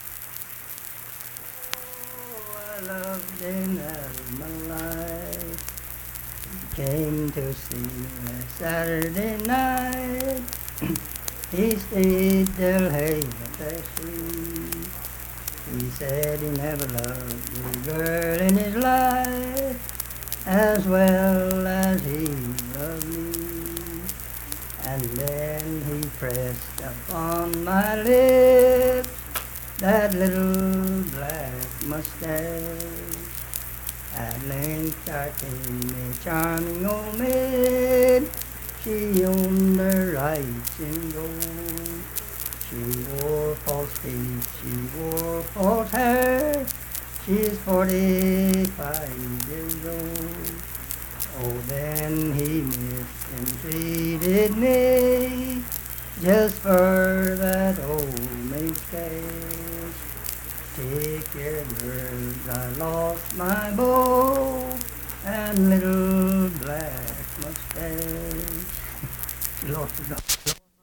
Unaccompanied vocal music
Verse-refrain, 8(2).
Voice (sung)
Lincoln County (W. Va.), Harts (W. Va.)